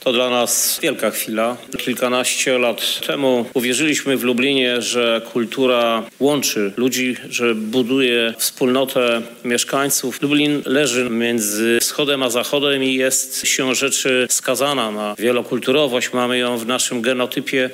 Spotkanie odbyło się w Warszawie w siedzibie Ministerstwa.
Krzysztof Żuk– mówi Krzysztof Żuk, Prezydent Miasta Lublin.